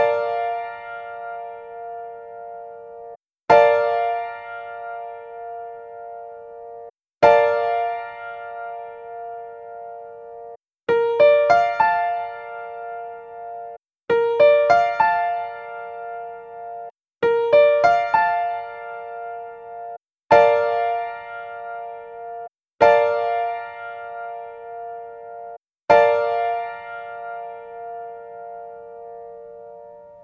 Bbmaj7 Piano Chord
Bbmaj7-Piano-Chord.mp3